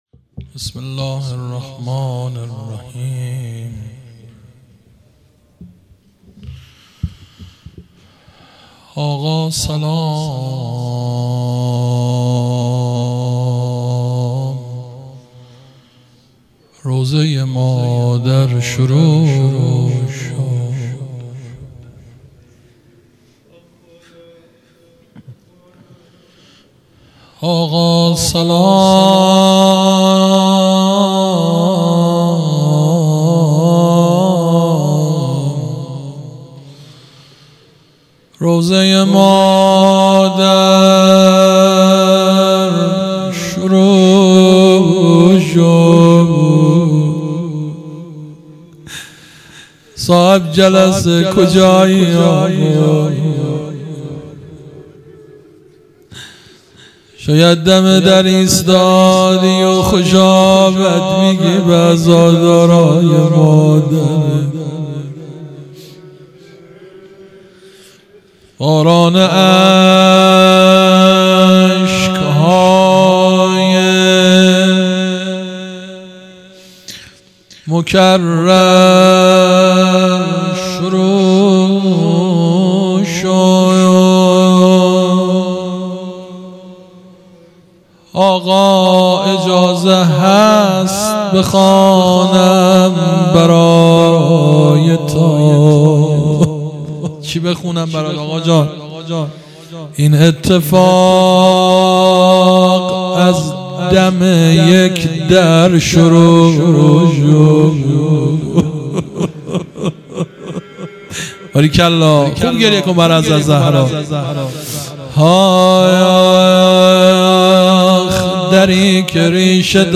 روضه | آقا سلام مداح